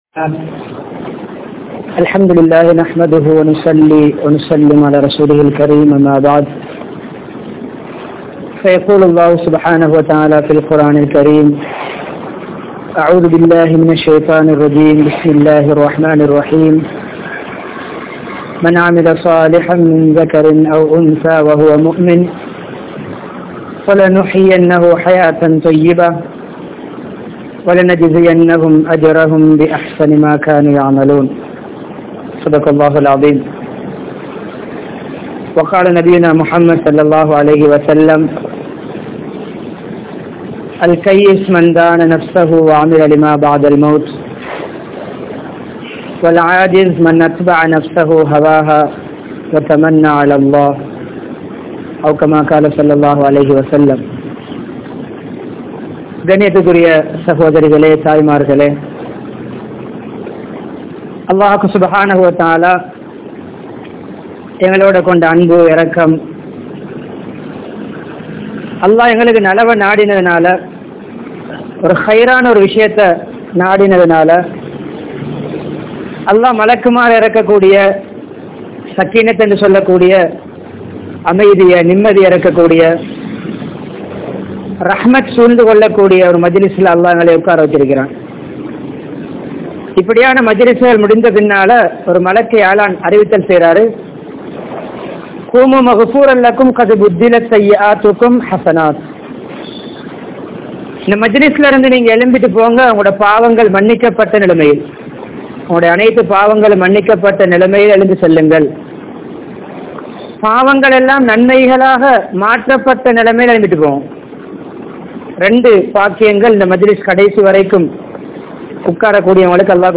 Pengalukku Maththiel Dheenin Avasiyam (பெண்களுக்கு மத்தியில் தீனின் அவசியம்) | Audio Bayans | All Ceylon Muslim Youth Community | Addalaichenai
Jamiul Falah Jumua Masjidh